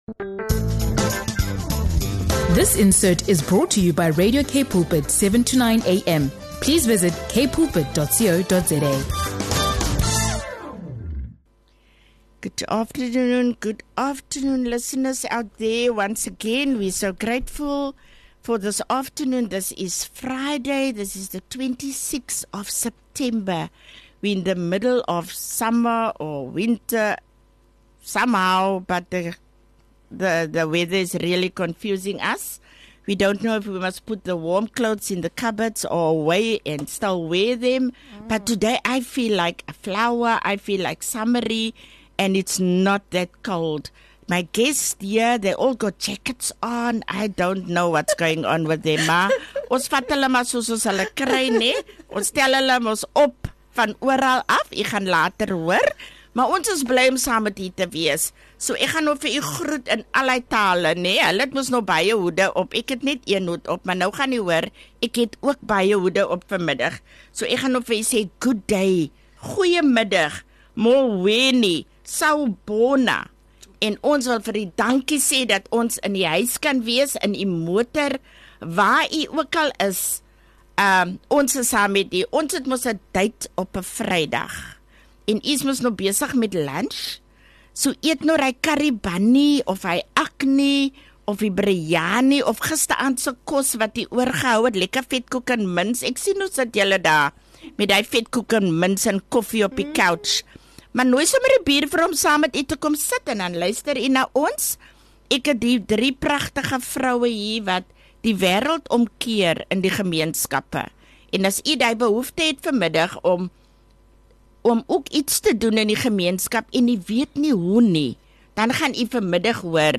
Joined by three remarkable women
Multilingual greetings, local flavor, scripture from Isaiah 58:10, and a reminder to be "Jesus Freaks" — this episode is a vibrant mix of faith, culture, and action.